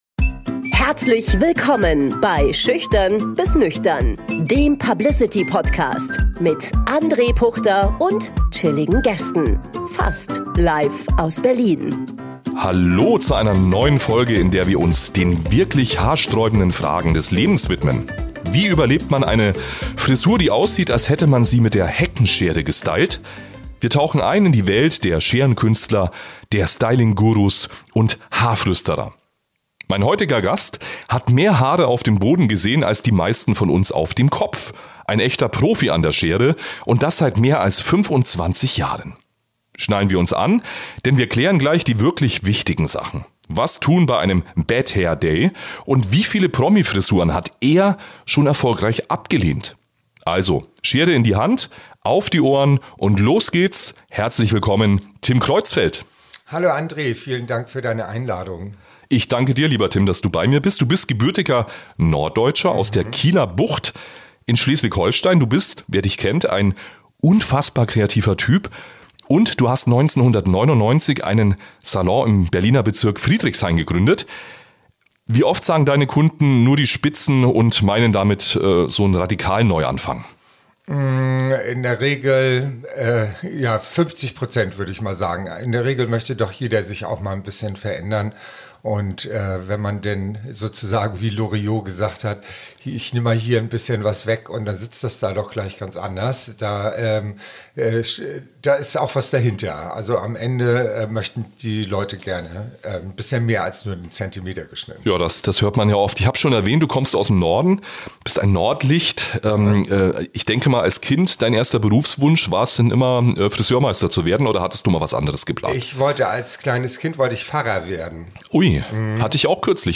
Ein Gespräch über wilde Kundenwünsche, „Brad-Pitt-Fotos“ auf dem Handy, über Trends, die bitte nie wiederkommen sollten – und über die harte Realität hinter dem Glamour: Meisterpflicht, drei Jahre duale Ausbildung, körperliche Belastung und die existenzbedrohenden Lockdowns während Corona, als sein Salon mit acht Angestellten plötzlich vor dem Aus stand.